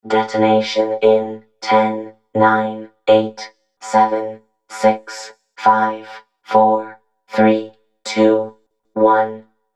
Голос робота, ведущего обратный отсчет до взрыва